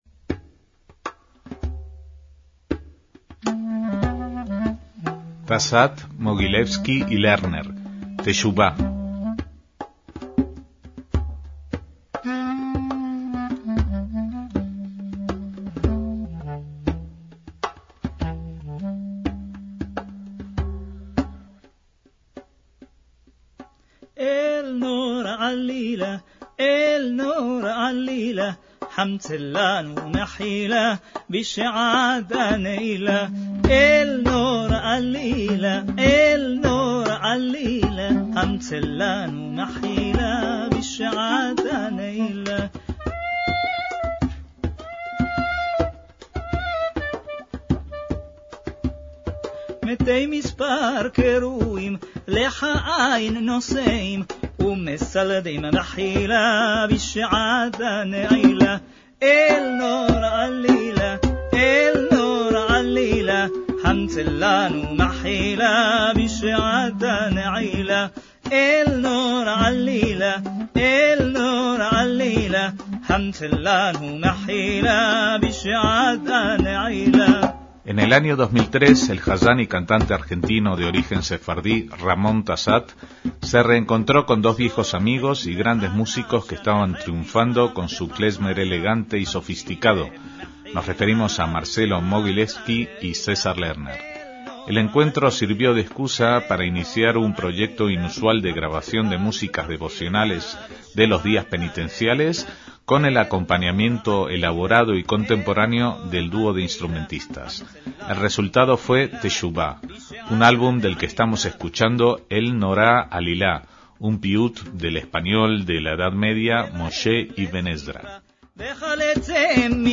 MÚSICA ESPIRITUAL
son los mejores exponentes del klezmer elegante que se hace en la ciudad natal de todos ellos
Y juntos suponen una masa crítica que lleva a las piezas de la liturgia ashkenazí de las fiestas sagradas a su máximo nivel, más allá de lo musical, a una experiencia espiritual que les invitamos a disfrutar